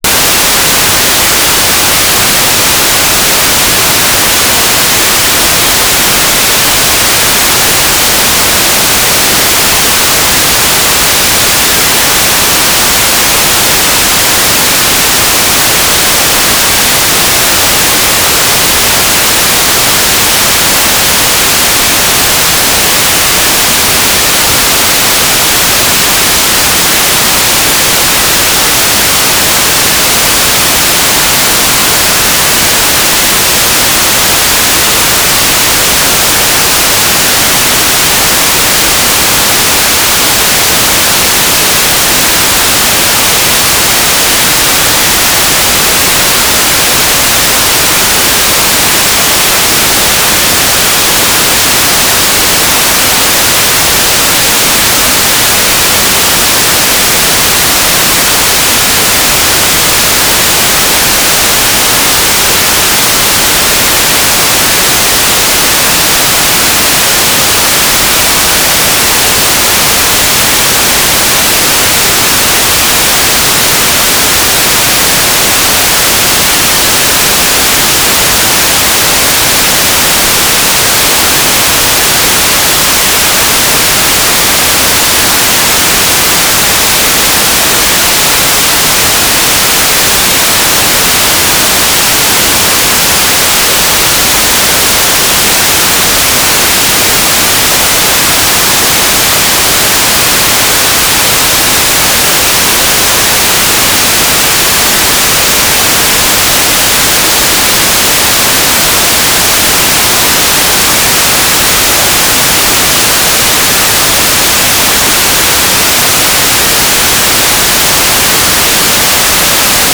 "transmitter_description": "S-band telemetry",